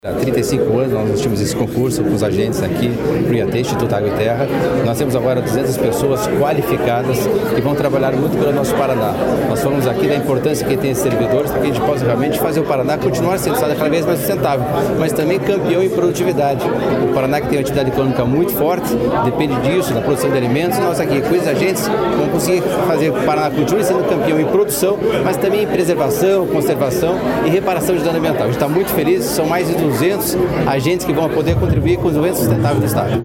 Sonora do secretário de Desenvolvimento Sustentável, Valdemar Bernardo Jorge, sobre a posse de 200 novos servidores no Instituto Água e Terra (IAT)